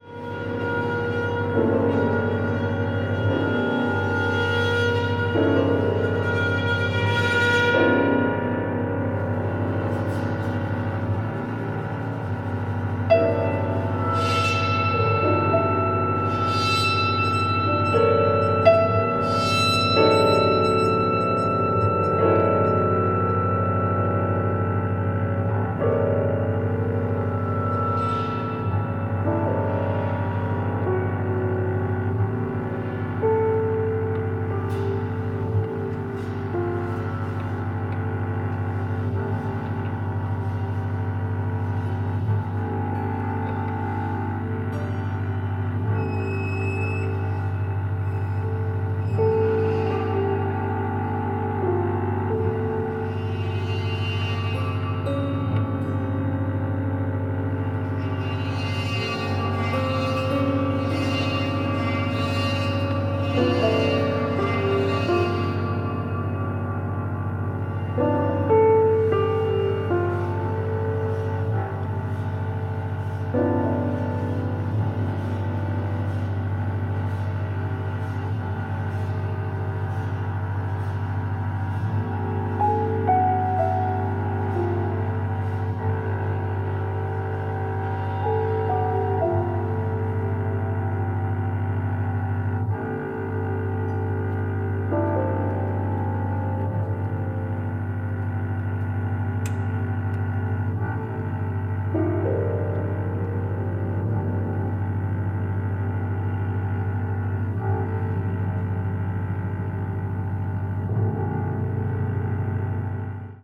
piano
contrabass